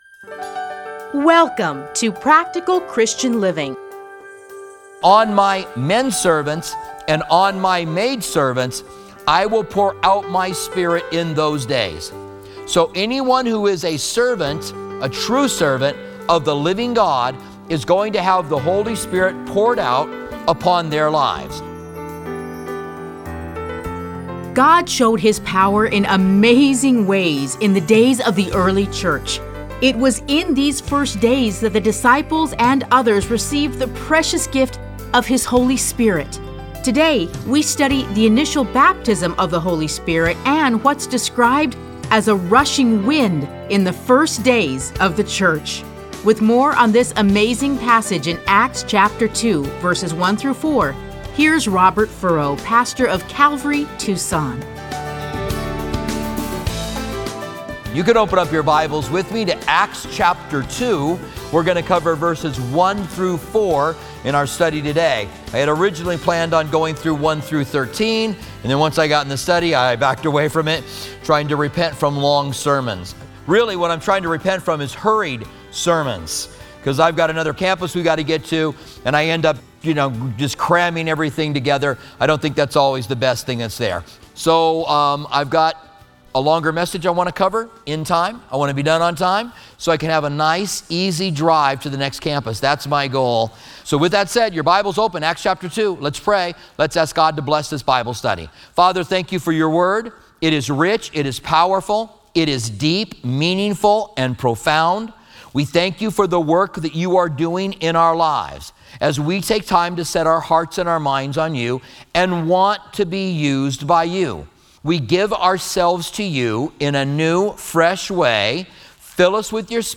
Listen to a teaching from Acts 2:1-4.